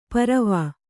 ♪ paravā